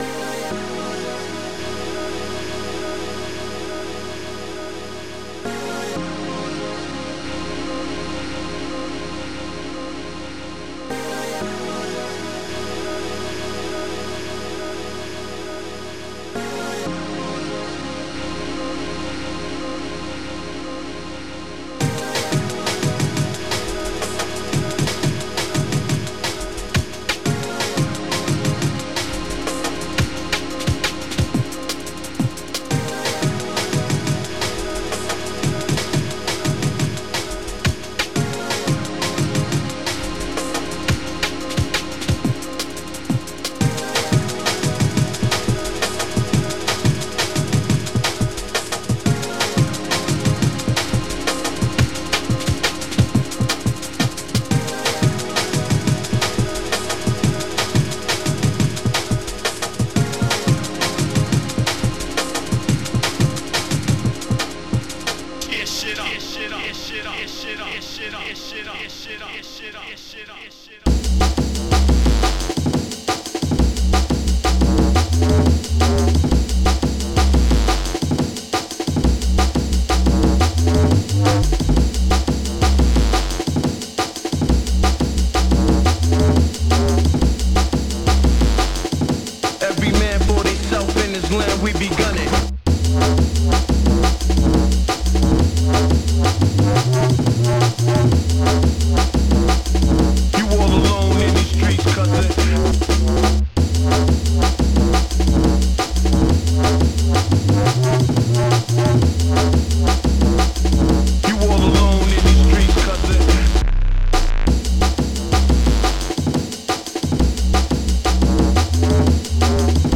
Protracker and family
hope u like my bass-
SYNTH.IFF